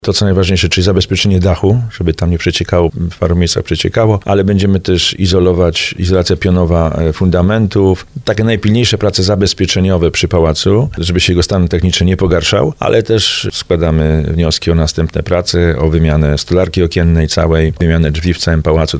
Jak mówi burmistrz Żabna Tomasz Kijowski, prace przy renowacji zabytku są konieczne.